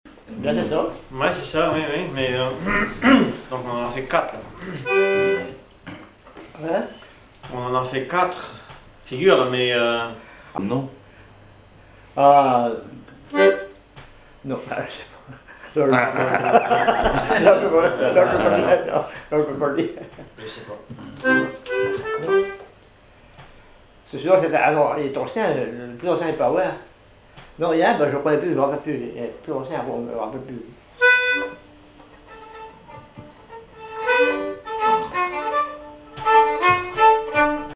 Répertoire de bal au violon et accordéon
Catégorie Témoignage